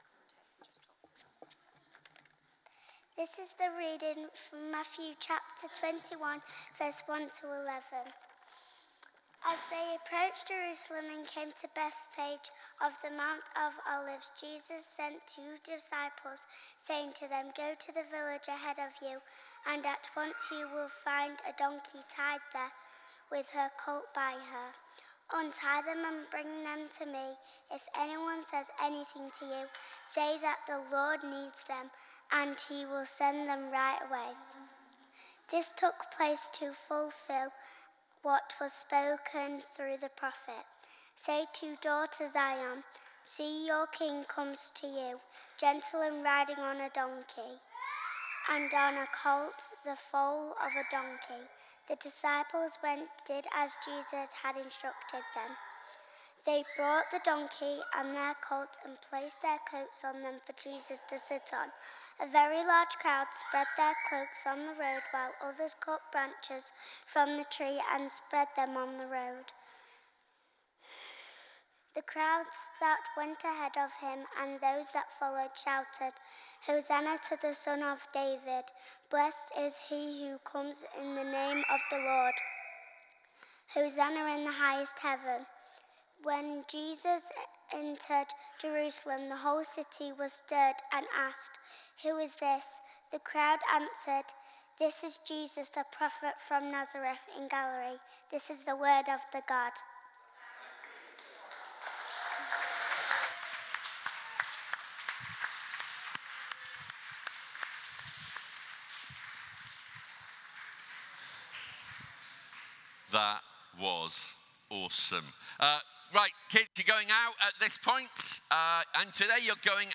Passage: Matthew 21: 1-11 Service Type: Sunday Morning